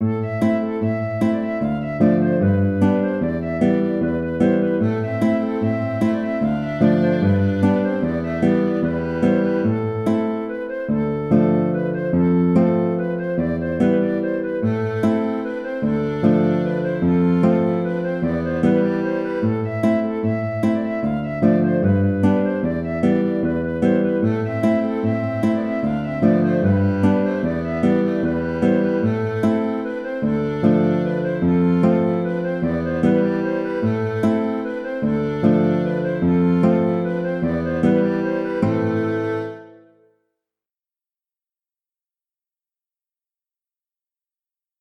Ridée de Pennec (Ridée 6 temps) - Musique bretonne
Le rythme d’une ridée 6 temps pouvant s’écrire de plusieurs façons, j’ai choisi d’alterner, respectivement à la phrase A et la phrase B, le rythme 6/4 et 3/4 , pour mieux mettre en évidence la particularité de chaque phrase. Il faut jouer cette ridée un peu plus vivement que dans le fichier audio présenté.